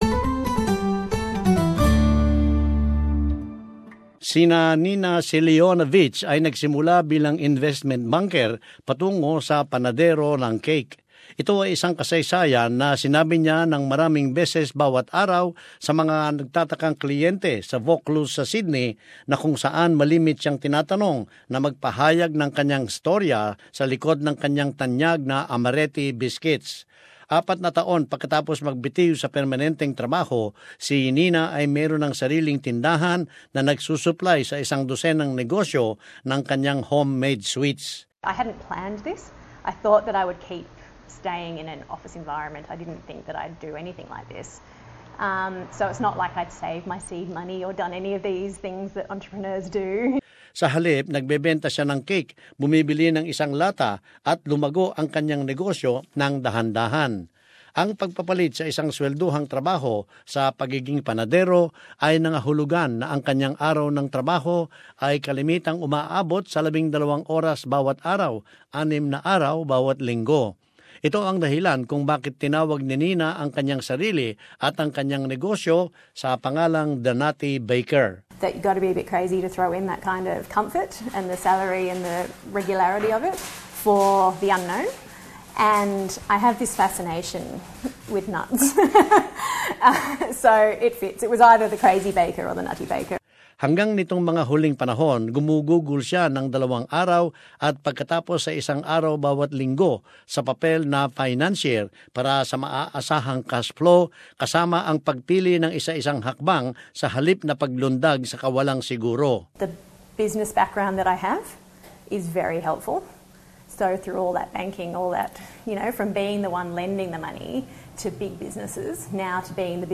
Dalawang taga-pondo ang nagsalaysay kung paano nilang pina-simulan ang kanilang bagong negosyo.